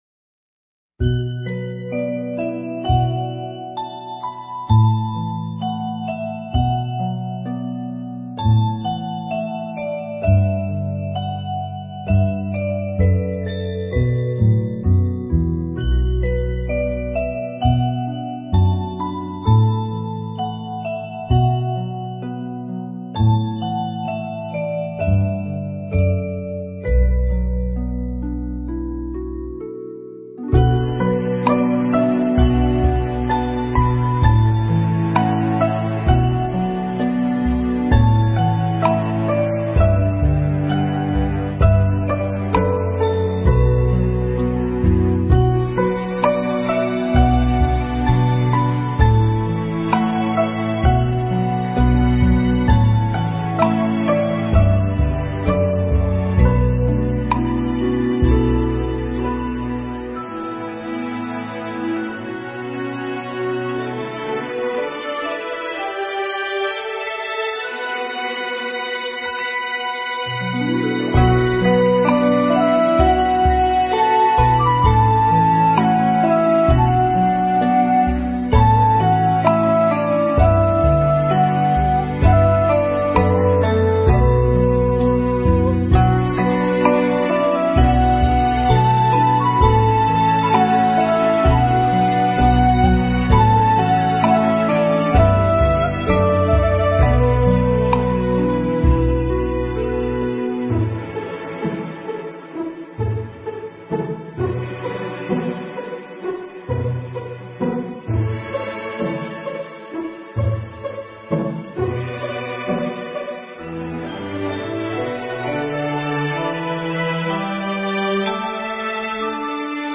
佛音 诵经 佛教音乐 返回列表 上一篇： 神通渡河 下一篇： 白净比丘尼 相关文章 大悲咒(童音版